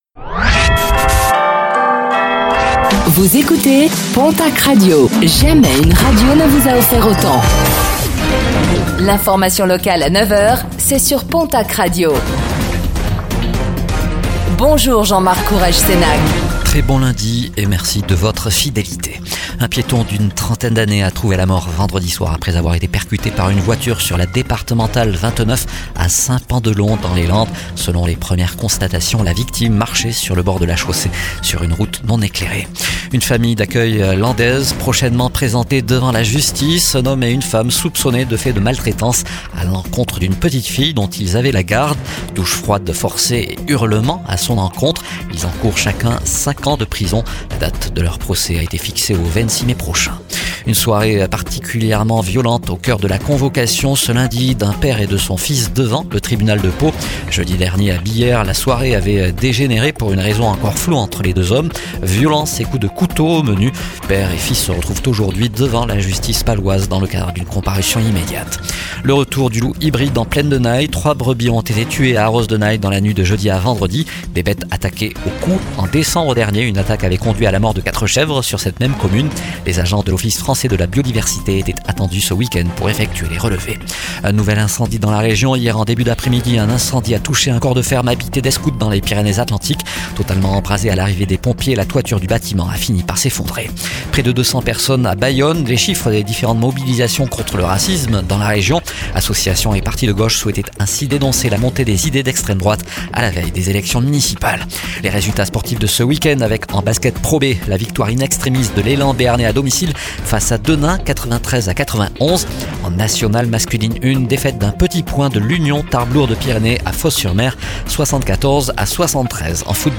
09:05 Écouter le podcast Télécharger le podcast Réécoutez le flash d'information locale de ce lundi 16 mars 2026